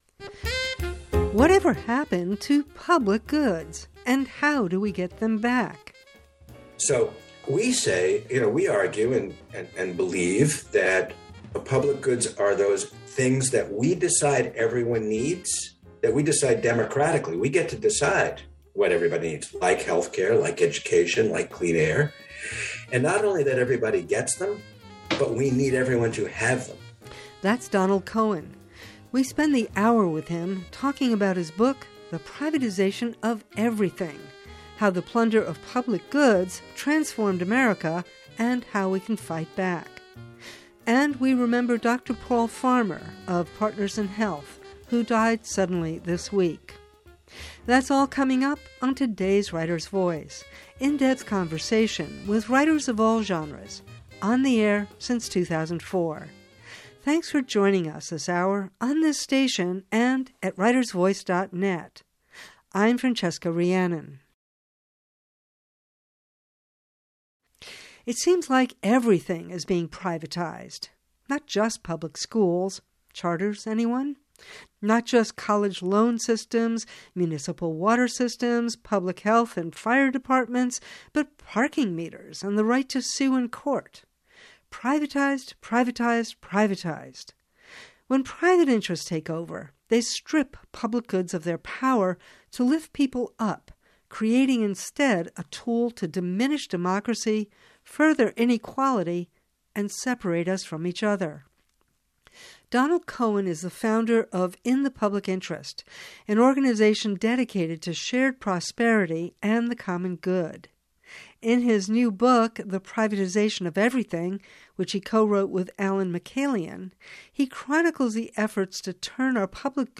Writers Voice— in depth conversation with writers of all genres, on the air since 2004.